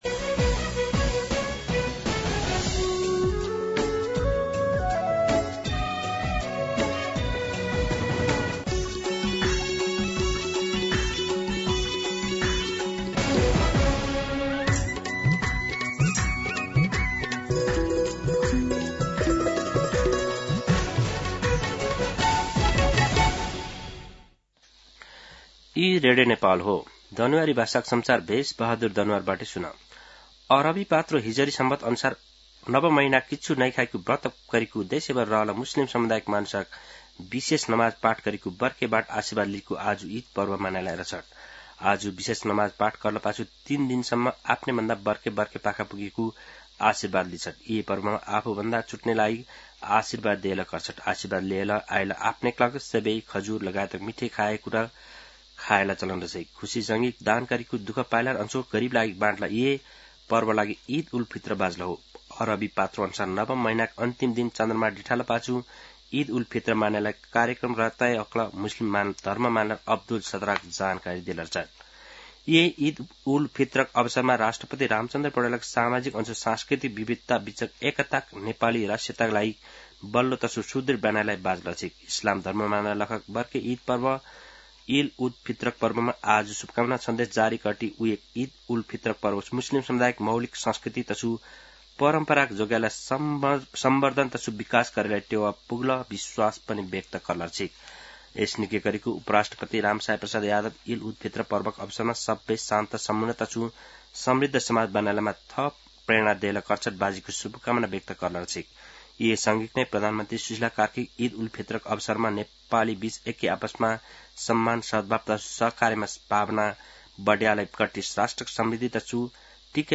दनुवार भाषामा समाचार : ७ चैत , २०८२
Danuwar-News-12-7.mp3